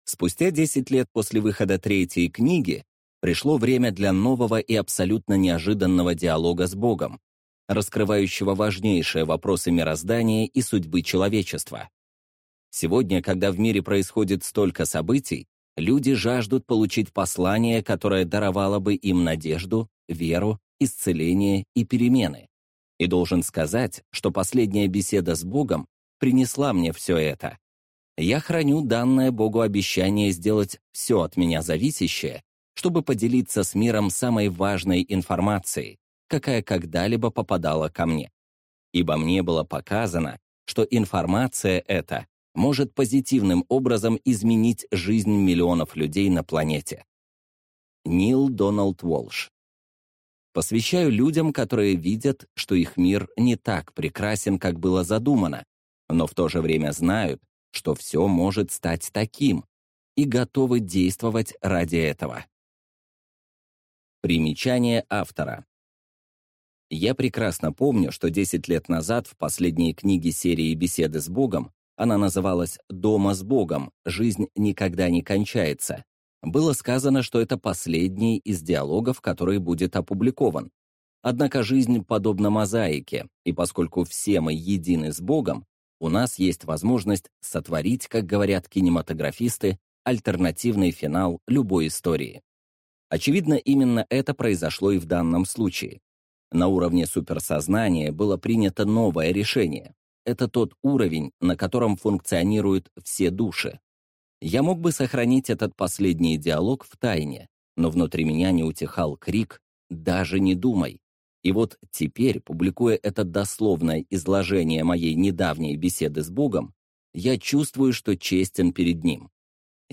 Аудиокнига «Беседы с Богом. Книга 4. Новый и неожиданный диалог о пробуждении человечества». Автор - Нил Дональд Уолш.